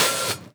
Medicated OHat 1.wav